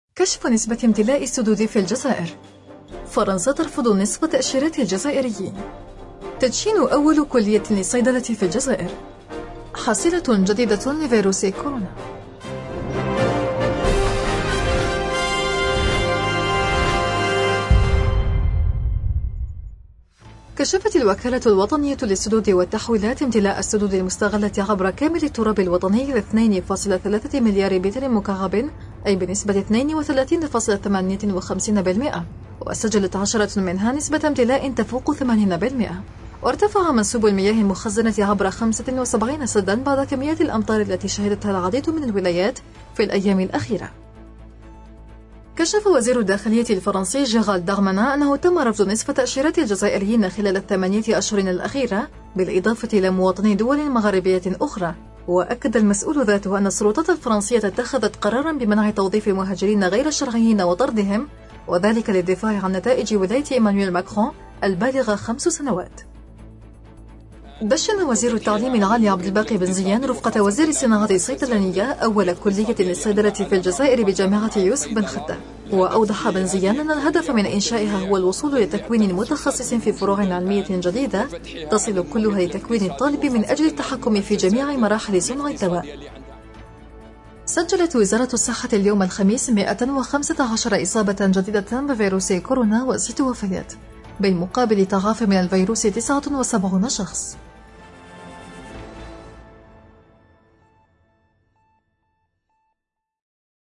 النشرة اليومية: سدود الجزائر تمتلئ بفضل تساقط الأمطار – أوراس